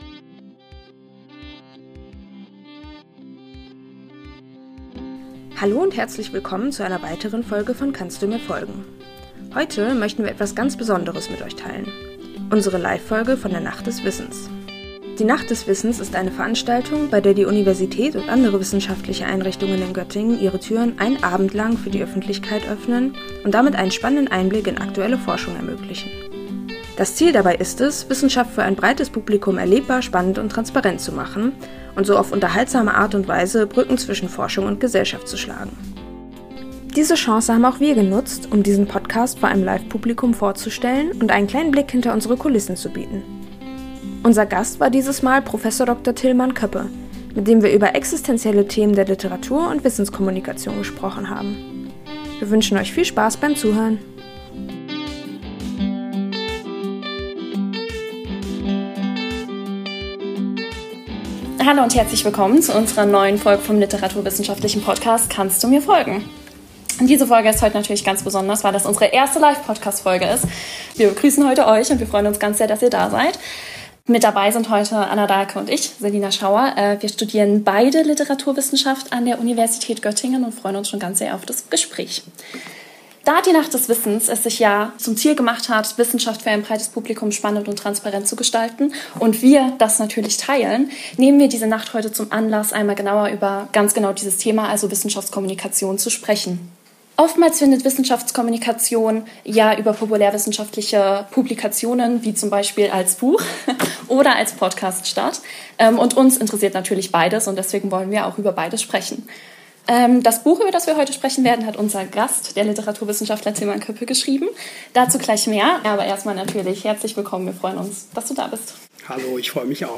Live! Wissenschaftskommunikation und existenzielle Themen in der Literatur